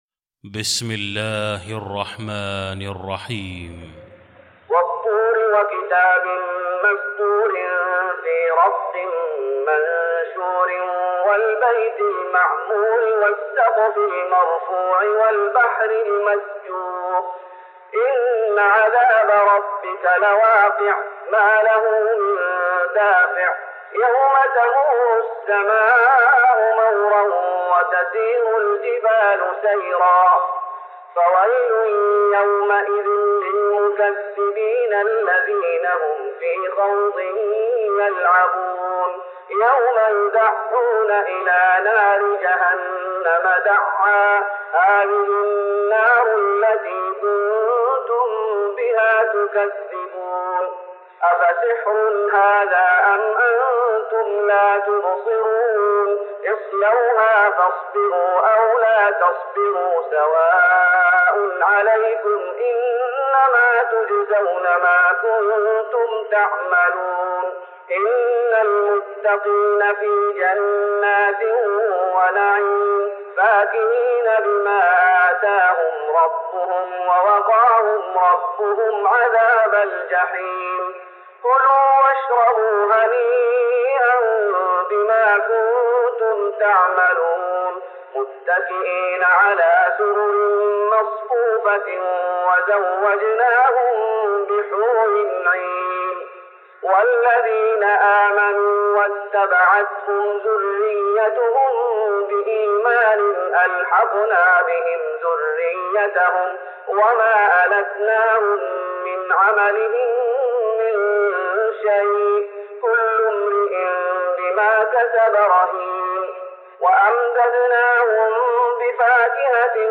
تراويح رمضان 1414هـ من سورة الطور Taraweeh Ramadan 1414H from Surah At-Tur > تراويح الشيخ محمد أيوب بالنبوي 1414 🕌 > التراويح - تلاوات الحرمين